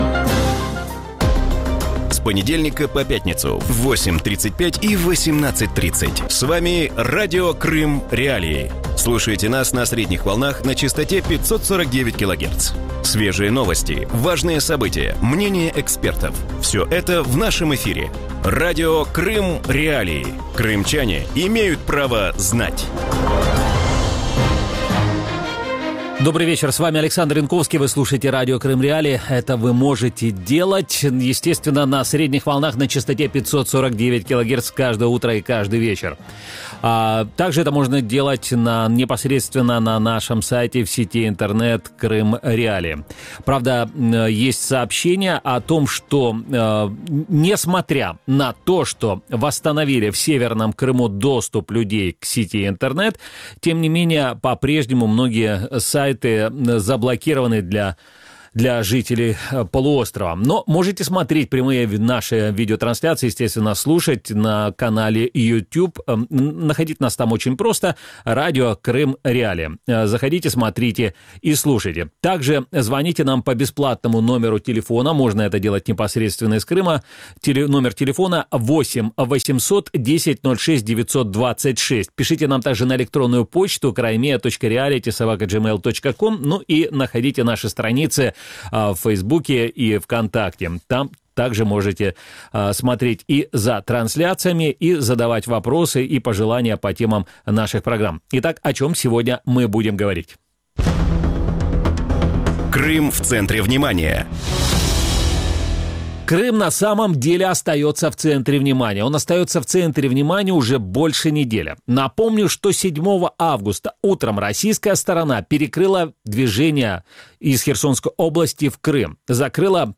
У вечірньому ефірі Радіо Крим.Реалії обговорюють розкриття нібито агентурної мережі українського Міністерства оборони на Кримському півострові. Яку мету переслідує Кремль, звинувачуючи Україну у відправці диверсійно-розвідувальних груп на територію анексованого Криму? Чи може послідувати за цими звинуваченнями ескалація конфлікту і як зможе протистояти Україна?